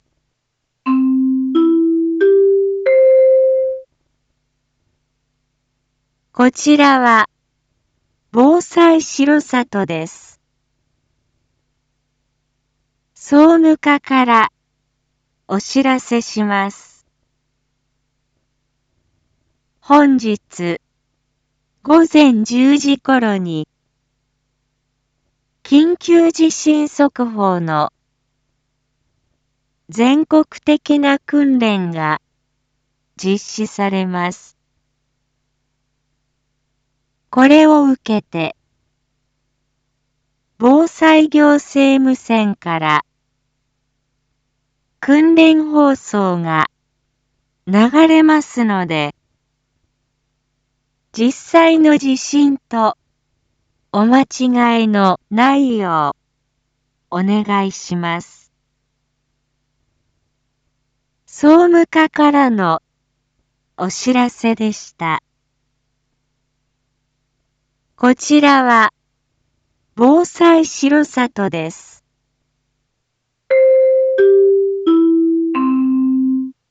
Back Home 一般放送情報 音声放送 再生 一般放送情報 登録日時：2023-06-15 07:01:18 タイトル：全国瞬時警報システム訓練の放送配信について インフォメーション：こちらは、防災しろさとです。